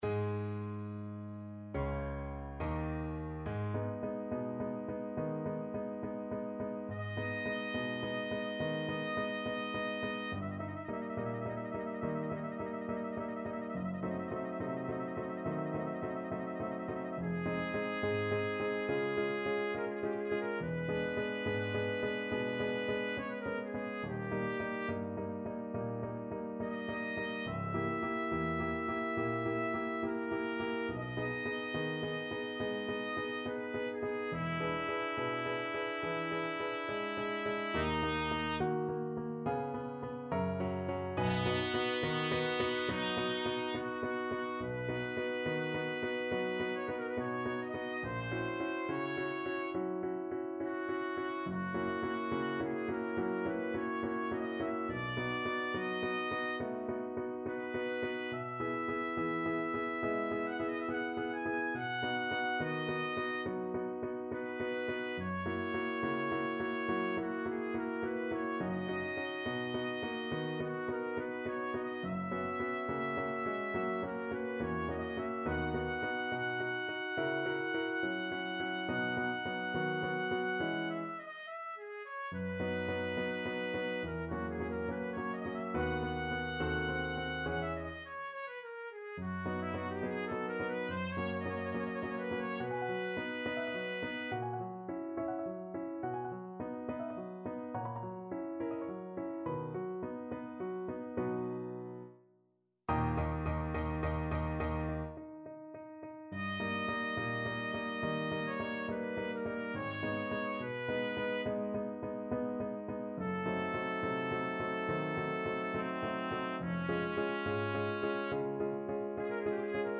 4/4 (View more 4/4 Music)
Andante = c.70
B4-Ab6
Trumpet  (View more Intermediate Trumpet Music)
Classical (View more Classical Trumpet Music)
hummel_trumpet_con_2_TPT.mp3